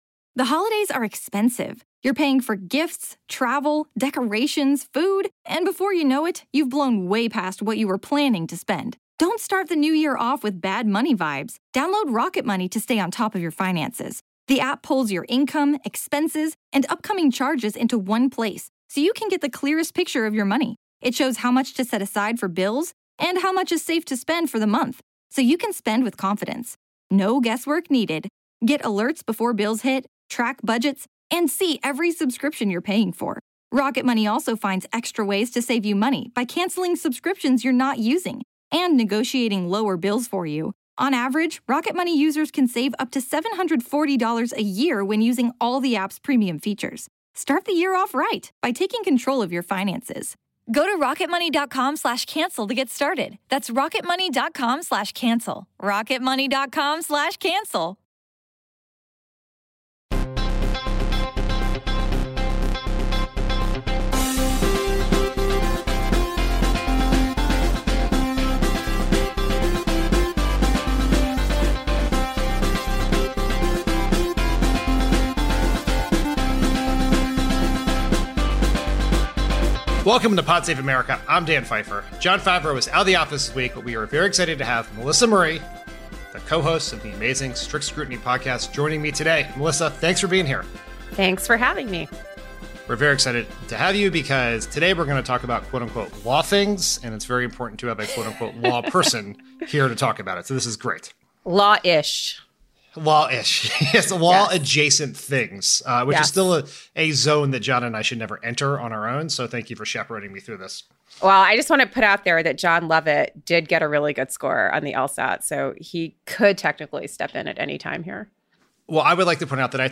Melissa Murray guest hosts as Donald Trump kinda, sorta mounts a defense in the rapidly moving criminal investigation into his habit of illegally storing highly classified documents at his beach house. White House Press Secretary Karine Jean-Pierre joins to discuss President Biden’s huge and long awaited decision to cancel up to $10,000 in student debt, and Arizona Secretary of State Katie Hobbs joins to talk about her campaign for Governor.